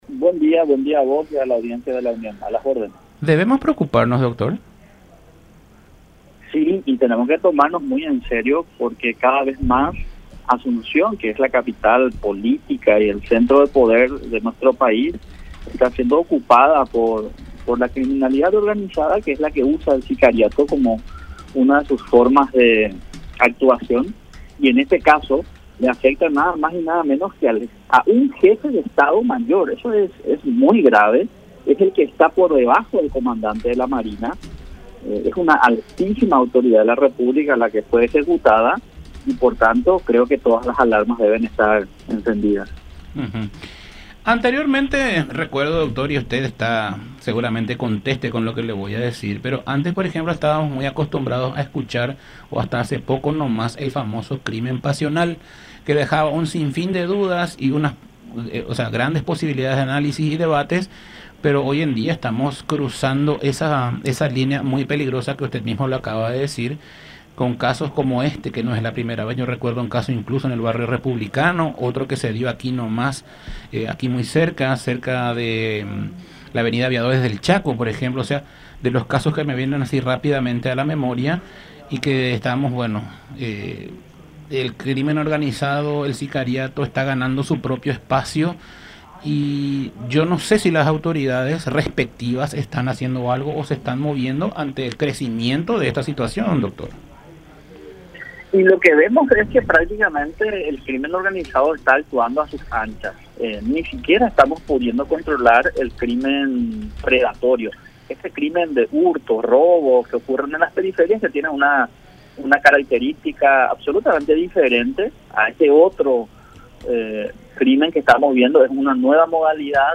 en diálogo con Todas Las Voces a través de La Unión